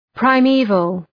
Προφορά
{praı’mi:vəl}